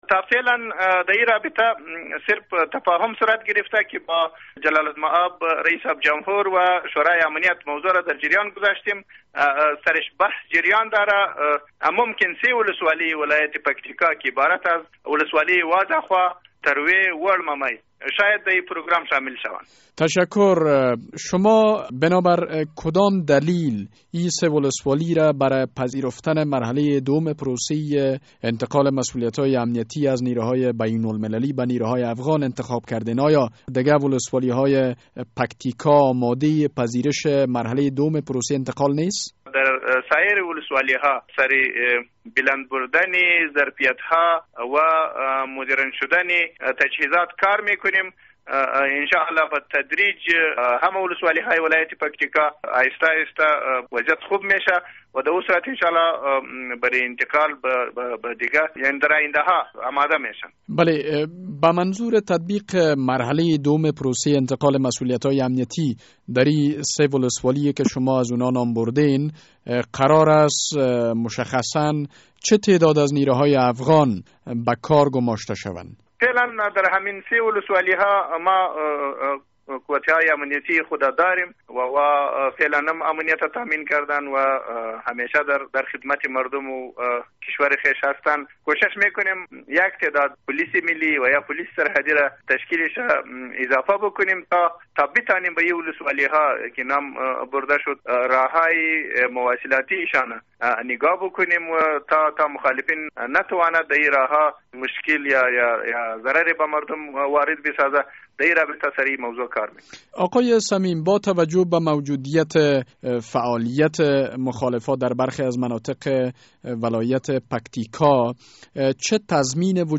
مصاحبه با والی پکتیکا در مورد مرحله دوم پروسه انتقال